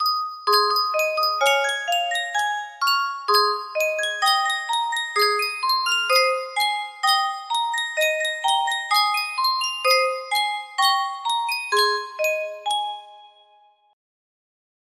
Sankyo Music Box - I Gave My Love a Cherry G7 music box melody
Full range 60